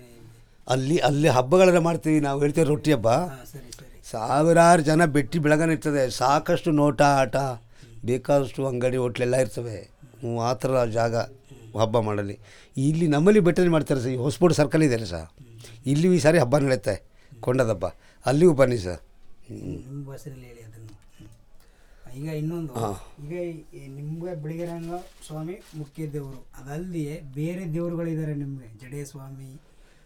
Traditional narrative about Chikka sampige